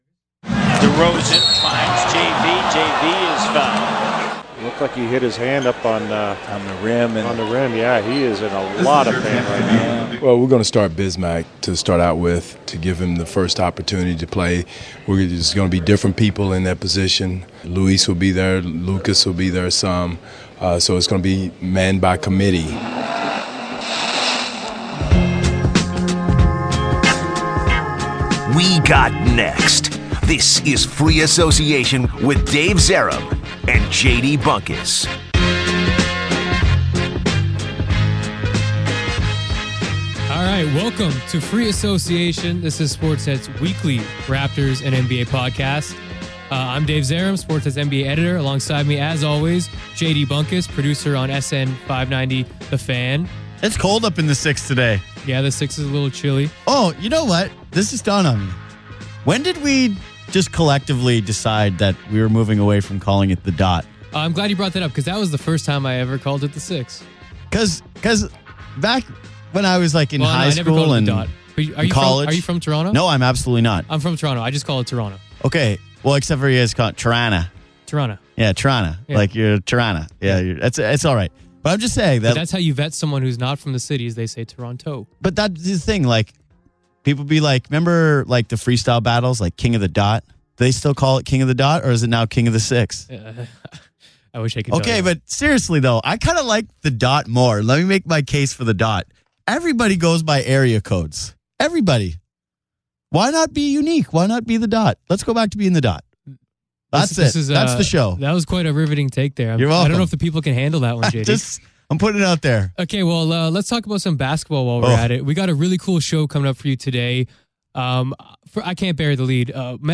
Also, Los Angeles Lakers forward Metta World Peace joins the show to go in-depth on his eventful career, his relationship with Larry Bird, the Malice at the Palace and the moment he hit rock bottom, close friend Lamar Odom, Kobe Bryant then-and-now, and a lot more.